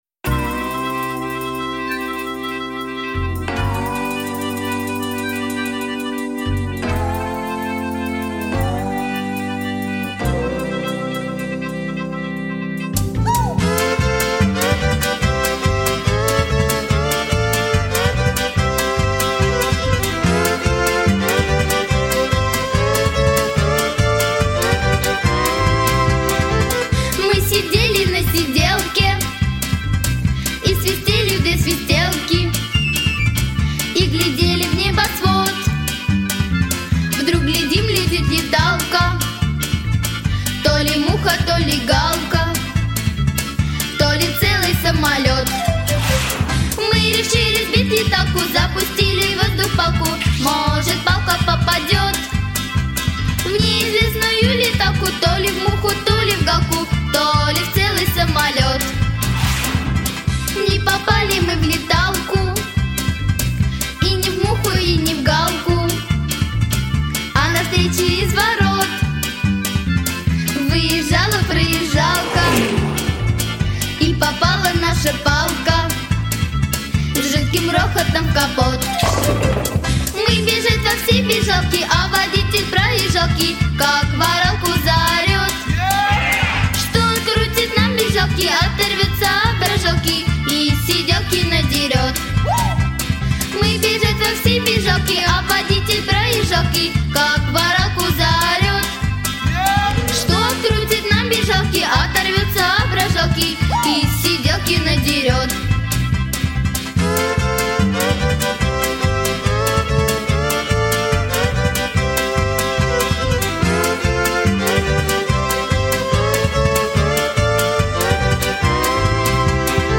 • Качество: Хорошее
• Жанр: Детские песни
шуточная